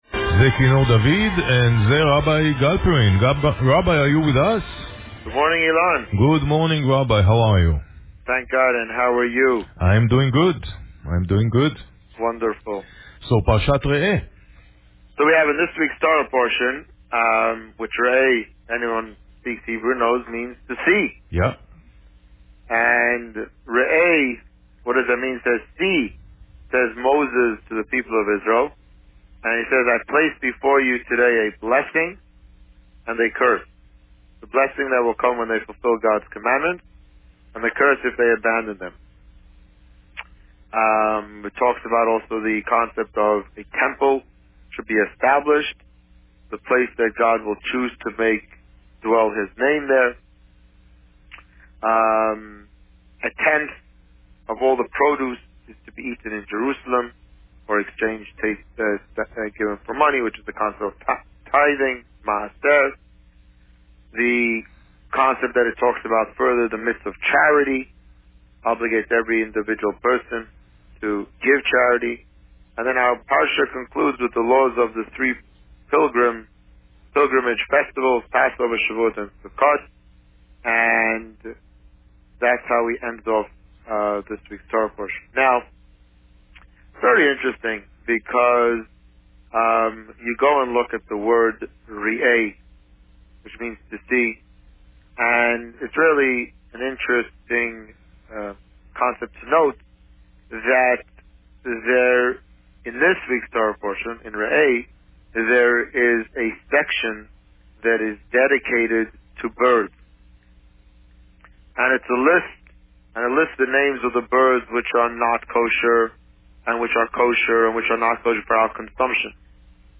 This week, the Rabbi spoke about Parsha Re'eh. Listen to the interview here.